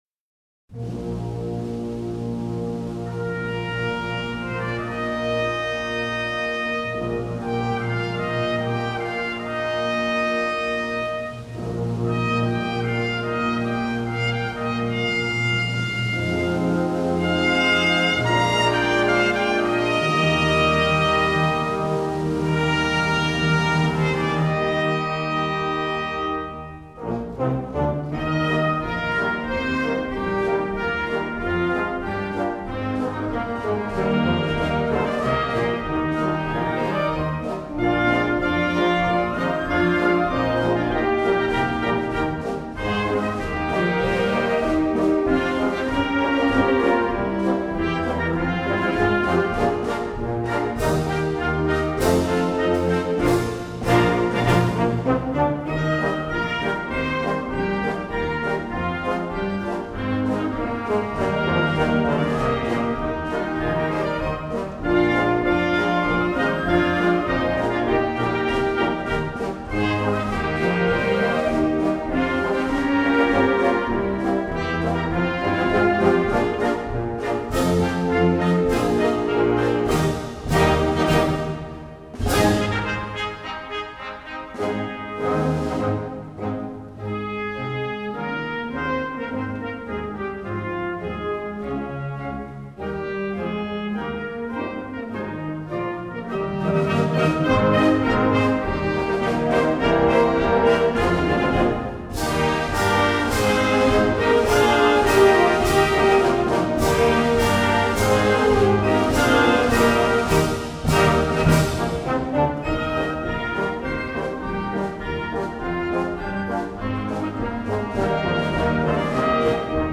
วงโยวาทิต